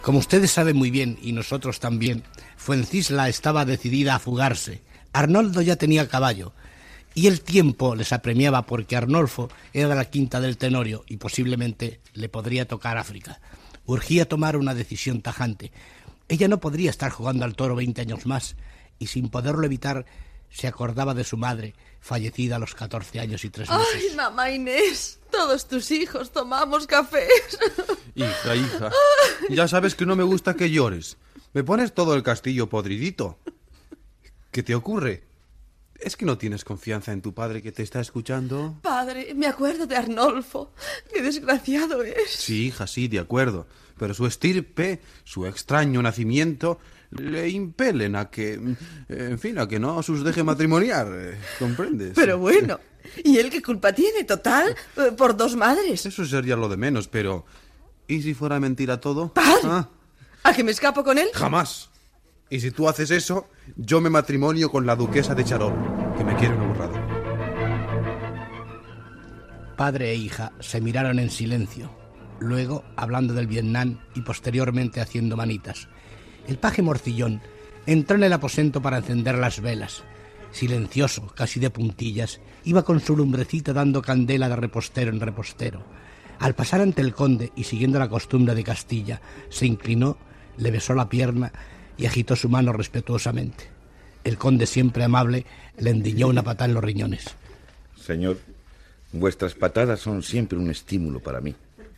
Fragment del serial.